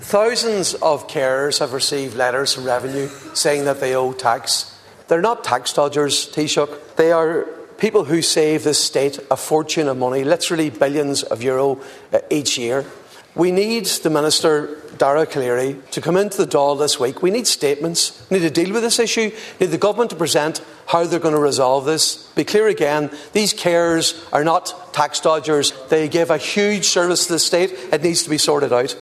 In the Dail this afternoon, Deputy Padraig MacLochlainn said this needs to be resolved…………..